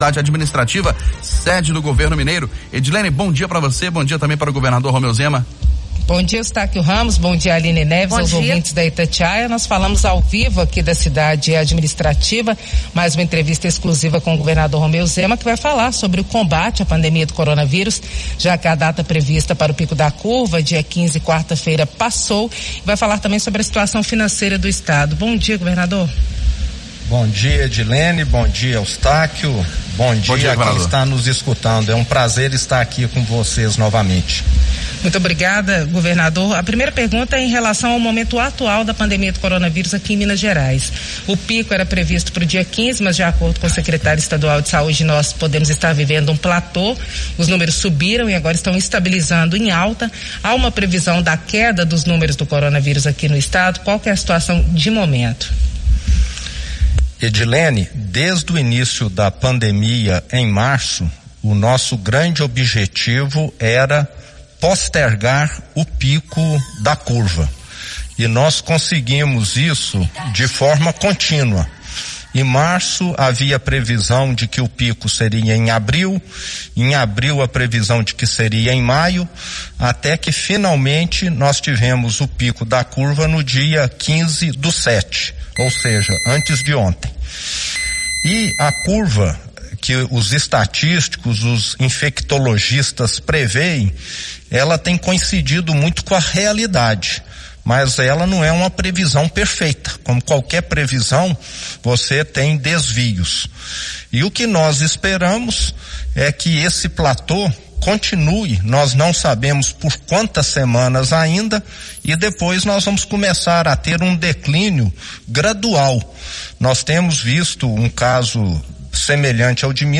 O governador de Minas Gerais Romeu Zema (Novo) afirmou, em entrevista exclusiva à Itatiaia, nesta sexta-feira, 17, que pode faltar sedativos usados para intubar pacientes graves em hospitais da rede pública.
entrevista-romeu-zema-falta-medicamentos.mp3